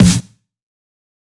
pcp_snare13.wav